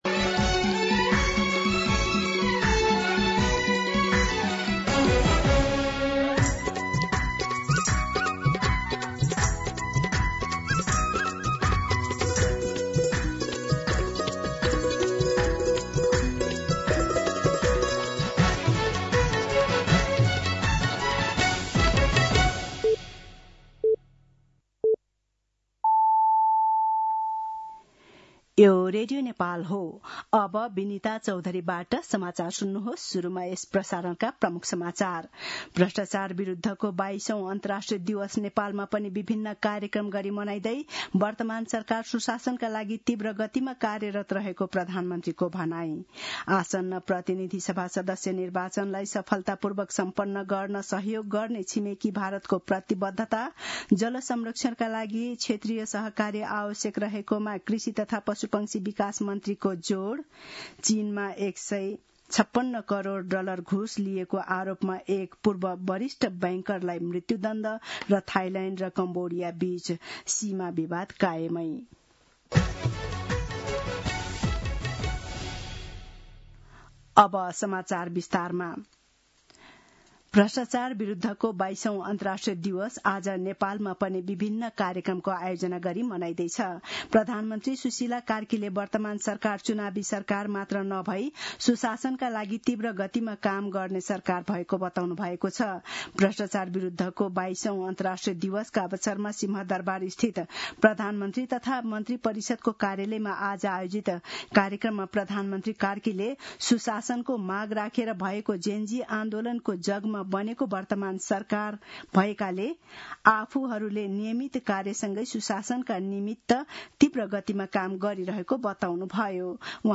दिउँसो ३ बजेको नेपाली समाचार : २३ मंसिर , २०८२